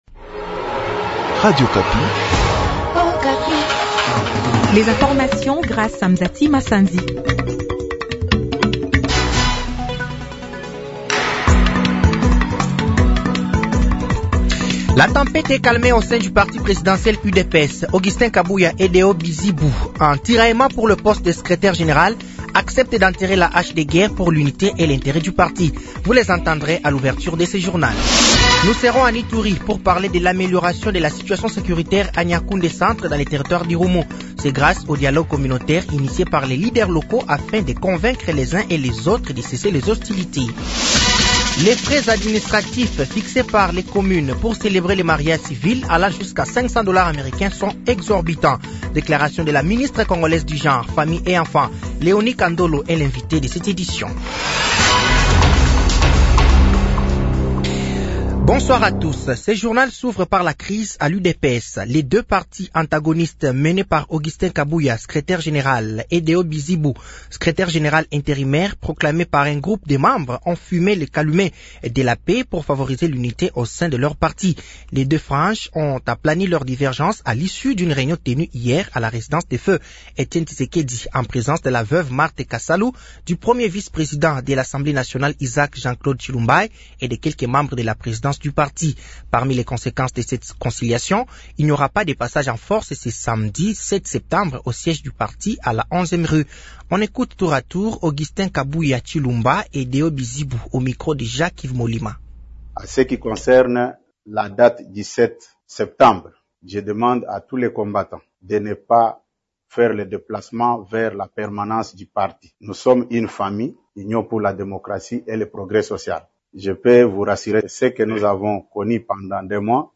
Journal français de 18h de ce vendredi 06 septembre 2024